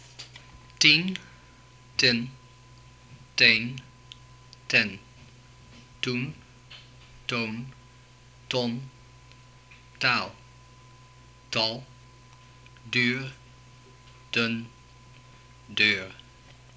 Here is a recording of a Dutch speaker using all of the vowels used in Dutch.
The Dutch speaker says /tin/, /tɪn/, /ten/, /tɛn/, /tun/, /ton/, /tɔn/, /tal/, /tɑl/, /dyr/, /dʏr/, and /dør/.